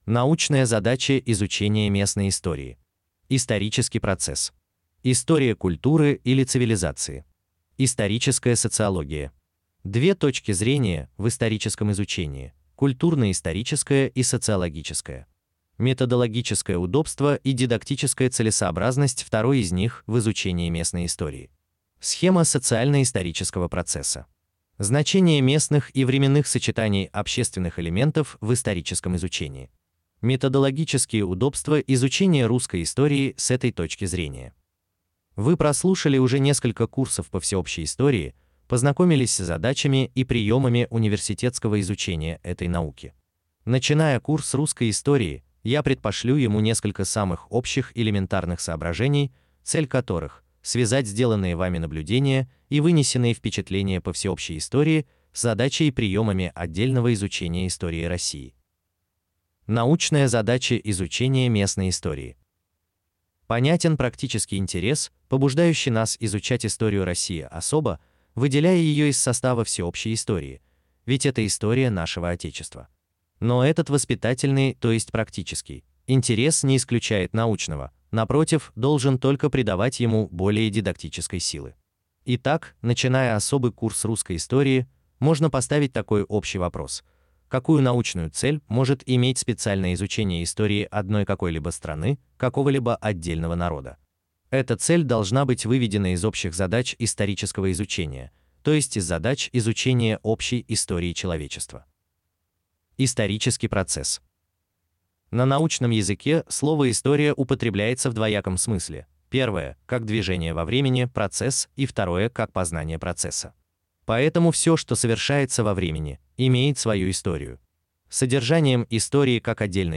Аудиокнига Русская история. Том 1 | Библиотека аудиокниг